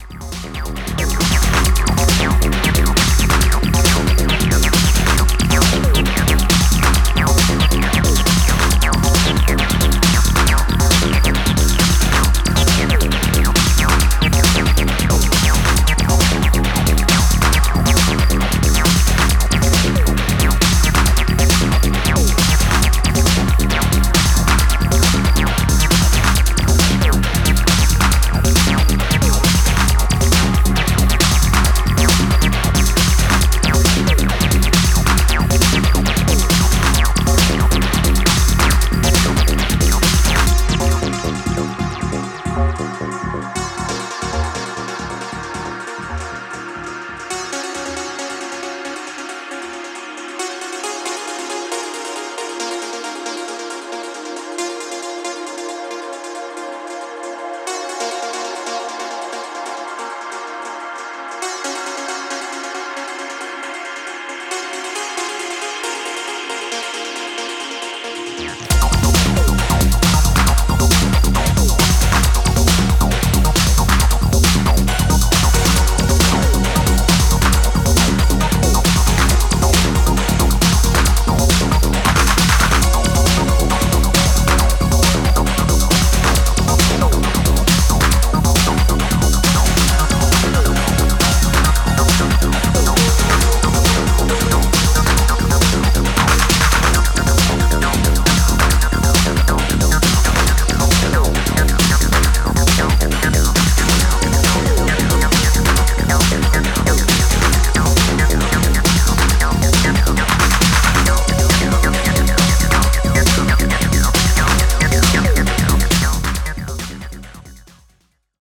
electro & detroit techno oriented four-tracker